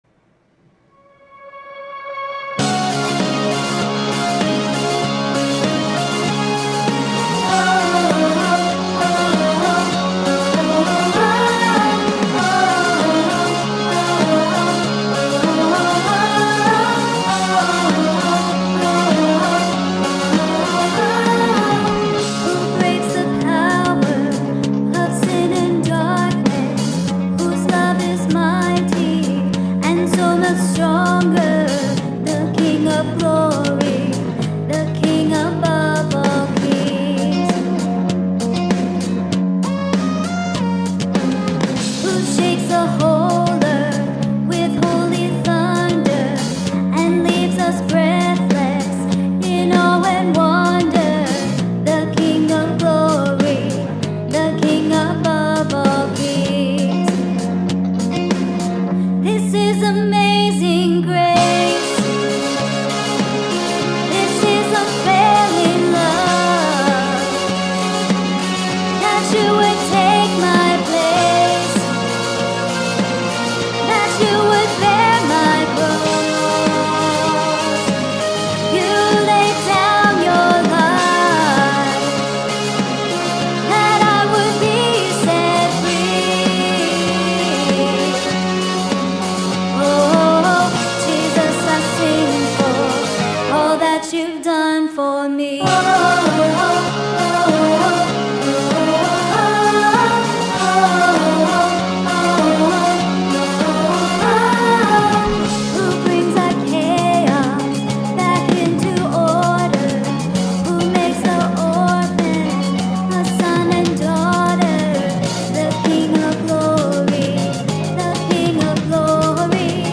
A King & His Kingdom Rehearsal Tracks
This-Is-Amazing-Grace-KeyDb-Reh-Track.mp3